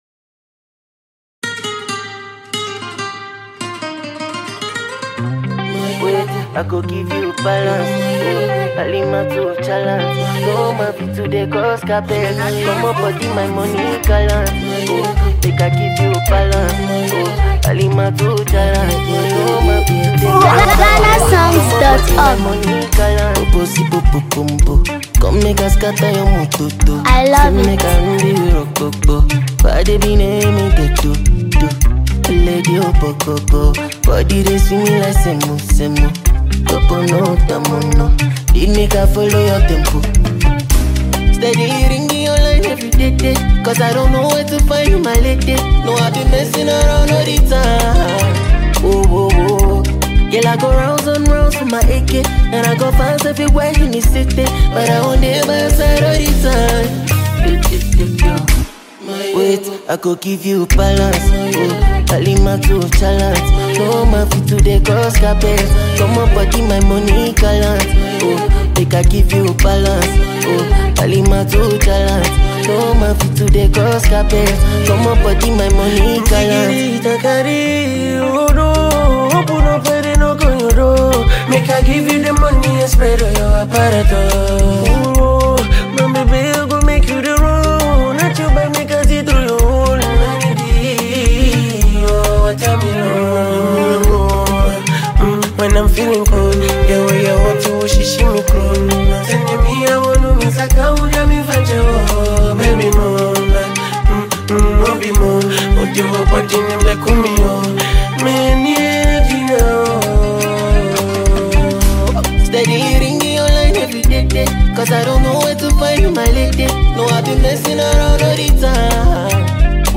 and the good-life vibe.